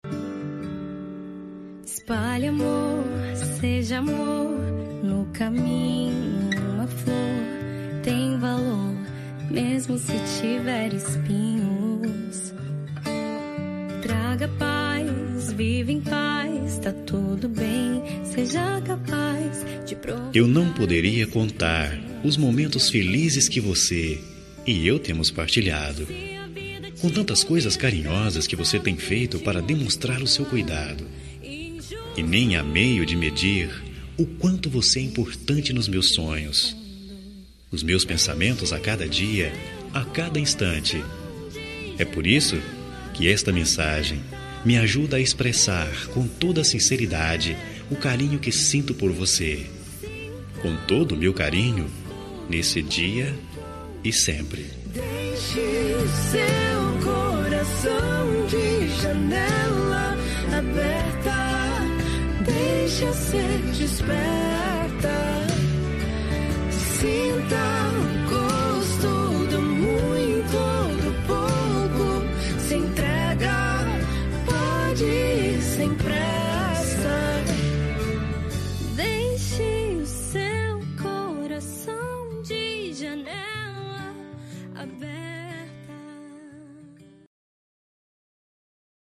Telemensagem Romântica -Voz Masculino – Cód: 4102 Linda
4102-romantica-agradecimento-masc.m4a